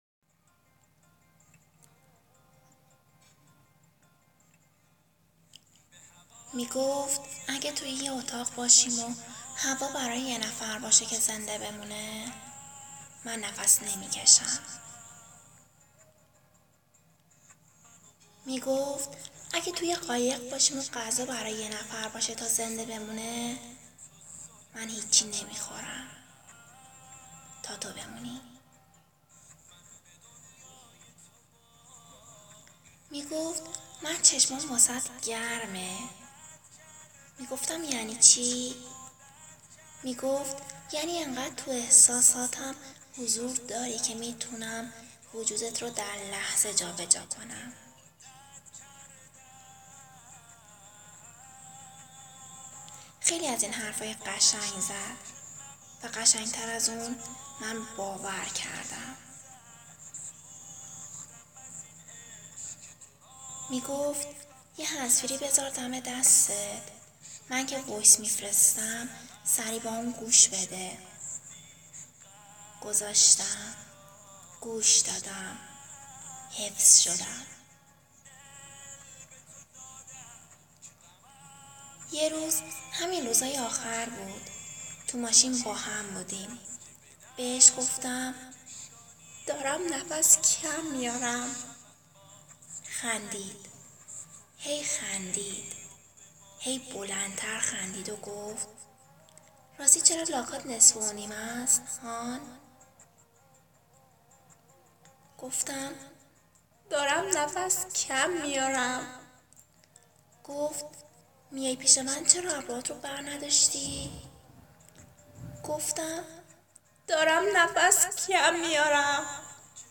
دکلمه صوتی به هوای ستاره
دکلمه-صوتی-به-هوای-ستاره.m4a